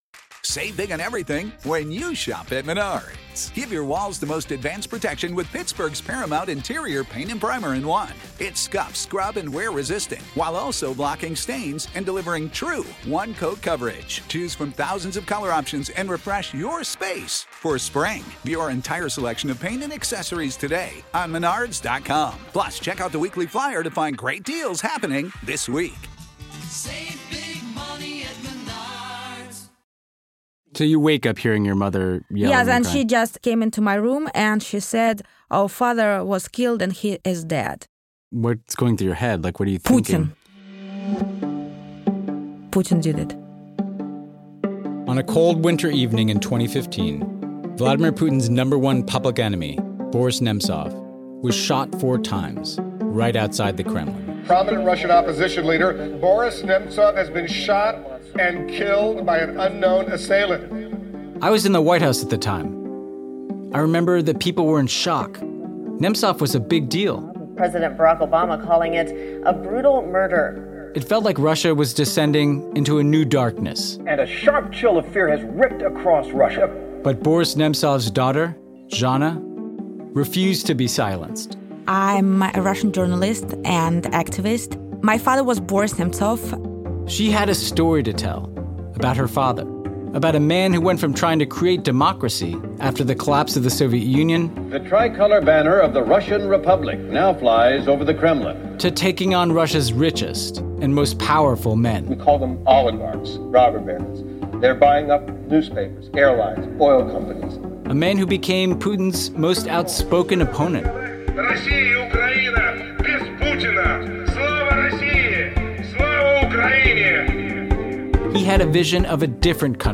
His daughter, journalist Zhanna Nemtsova, and co-host Ben Rhodes tell his story to find out what happened to an entire country – and what happens next.